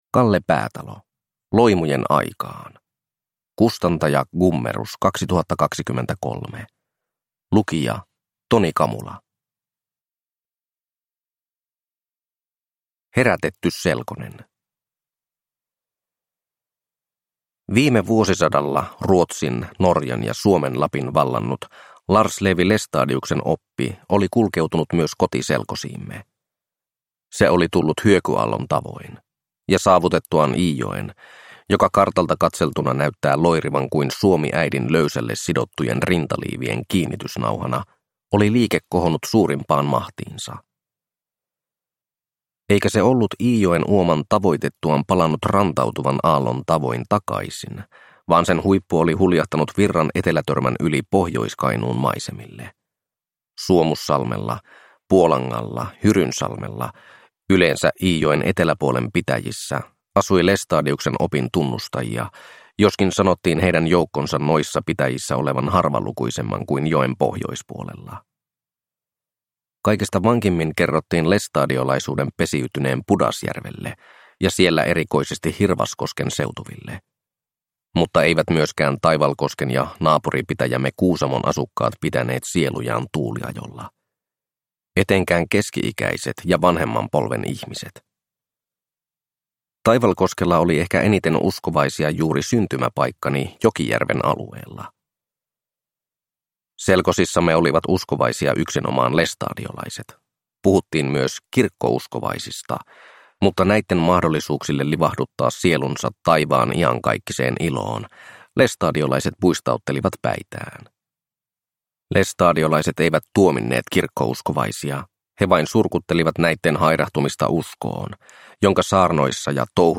Loimujen aikaan – Ljudbok – Laddas ner